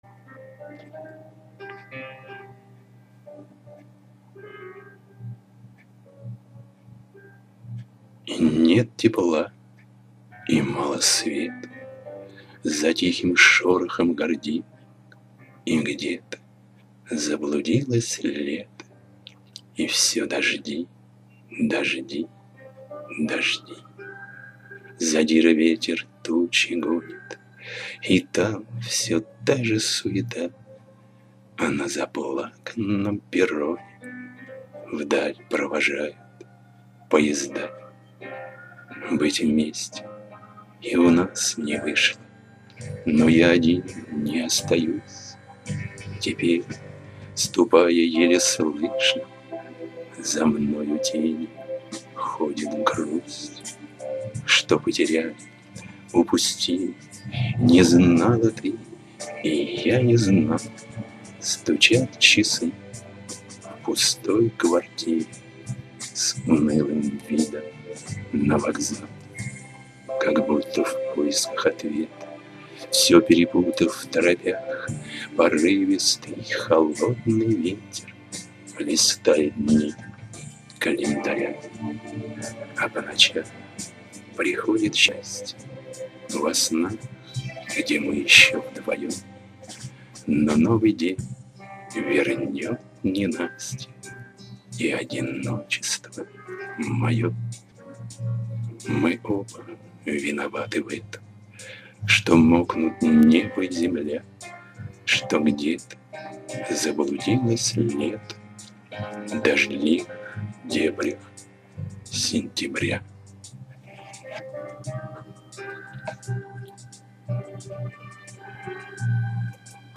Доброй летней субботы всем завалинцам, чтобы немножко охладиться в этот жаркий день предлагаю немного аудиорифм, прошу прощения за качество это первые опытные записи.
К сожалению то примитивное устройство, которым я пользуюсь делает ямы и создается впечатление, что я проглатываю буквы, а то и слоги.